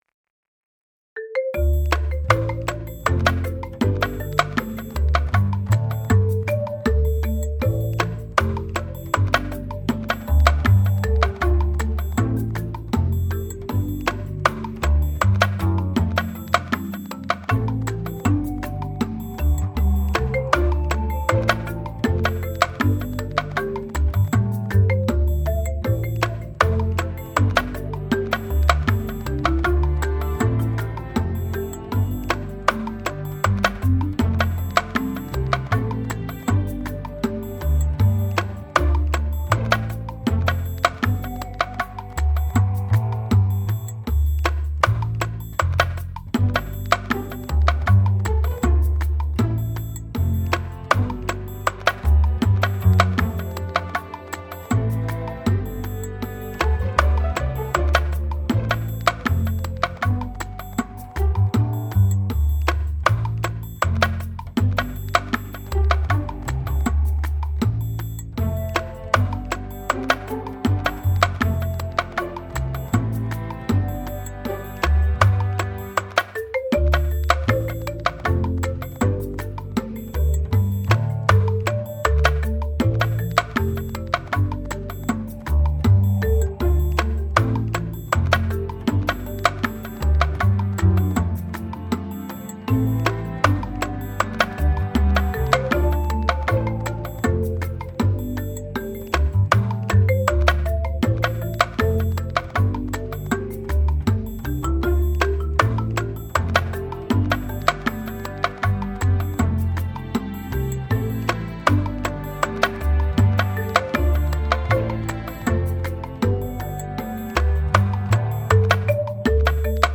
The original music was created by using piano and strings.